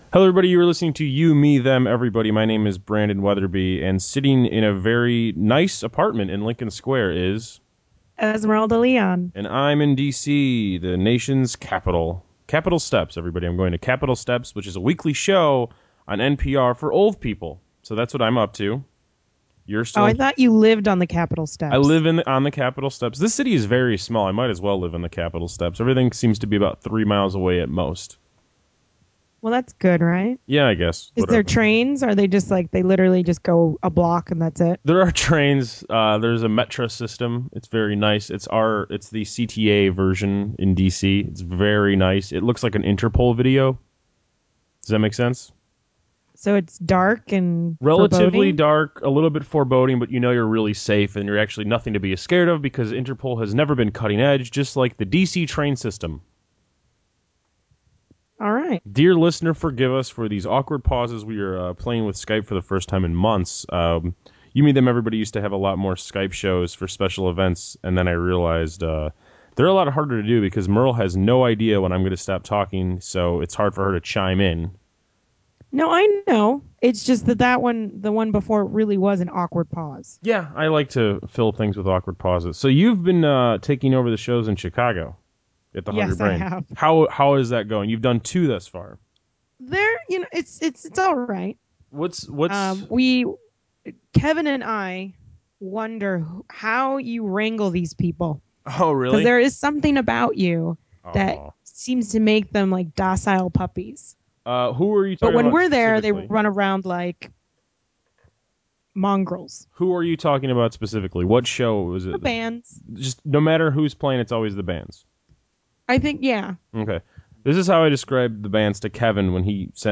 Our first Skype show in quite some time.
Back to mono!